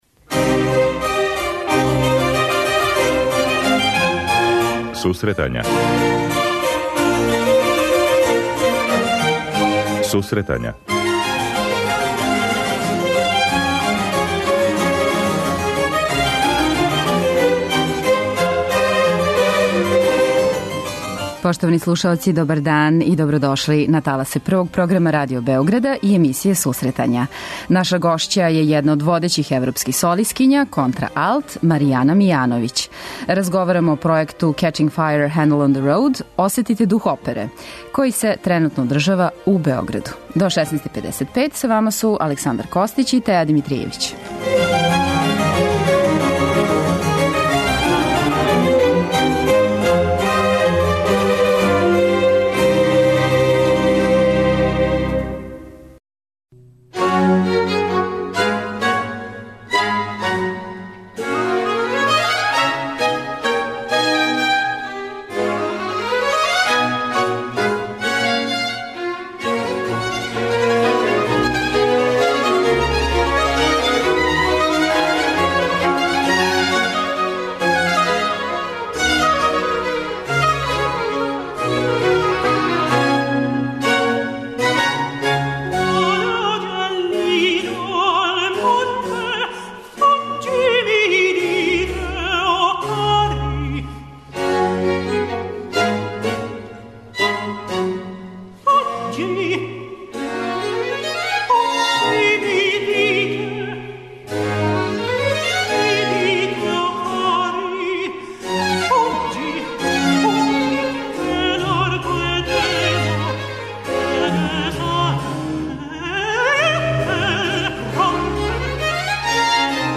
Гошћа емисије је једна од водећих европских солисткиња, контраалт Маријана Мијановић. Разговарамо о пројекту 'Catching Fire – Haendel on the Road-Osetite duh opere' који се тренутно одржава у Београду.